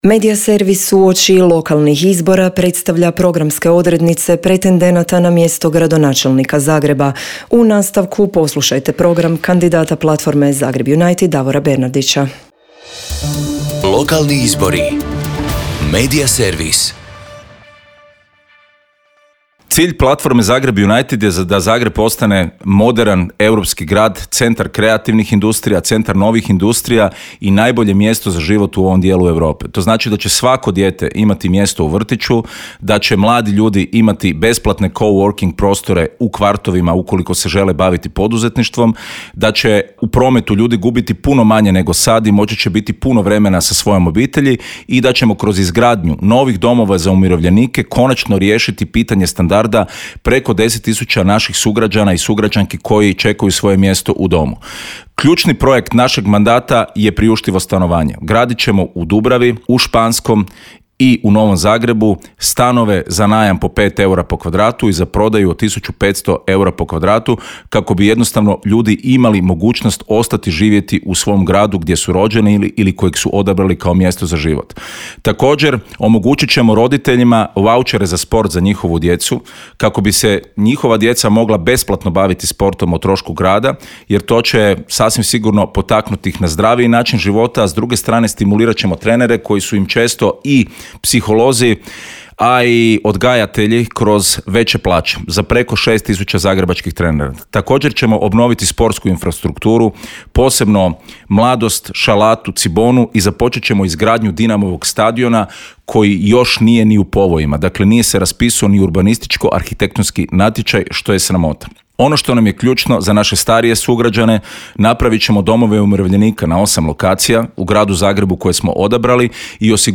ZAGREB - Uoči lokalnih izbora kandidati za gradonačelnicu/gradonačelnika predstavljaju na Media servisu svoje programe u trajanju od 5 minuta. Kandidat za gradonačelnika Zagreba ispred platforme Zagreb United Davor Bernardić predstavio je građanima svoj program koji prenosimo u nastavku.